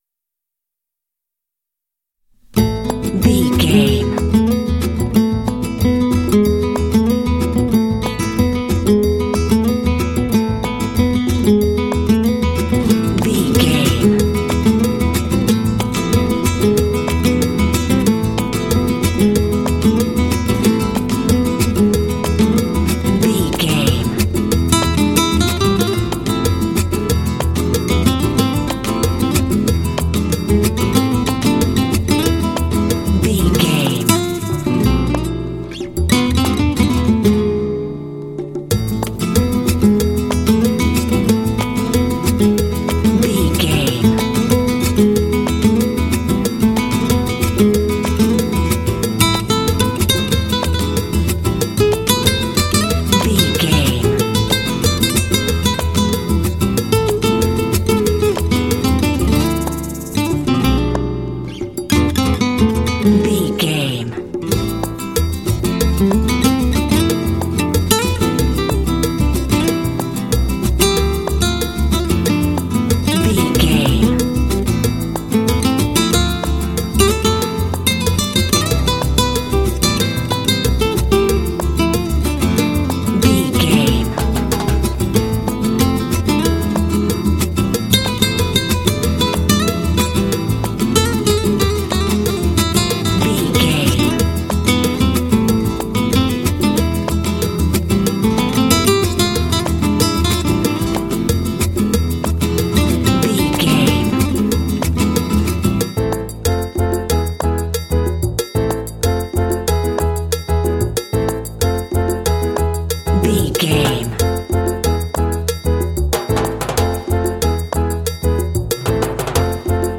Uplifting
Aeolian/Minor
smooth
percussion
acoustic guitar
piano
latin
Lounge
downtempo